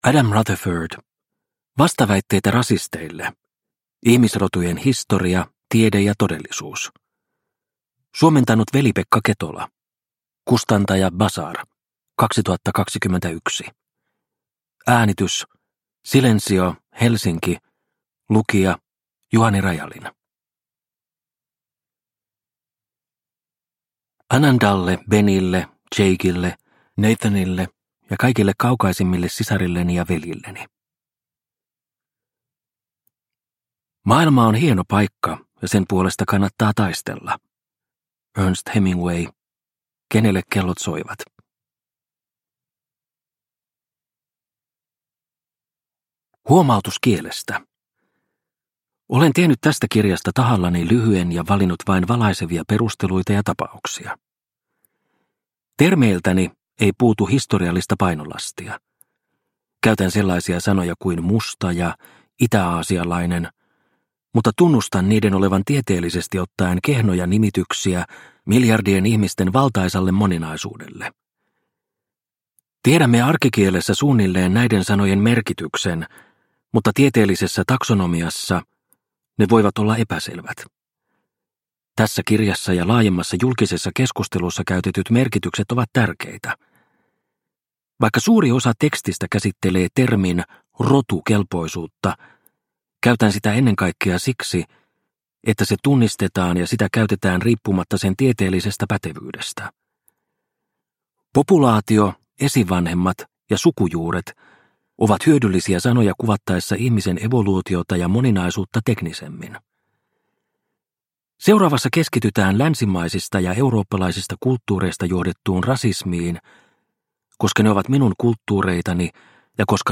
Vastaväitteitä rasisteille – Ljudbok – Laddas ner